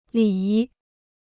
礼仪 (lǐ yí): etiquette; ceremony.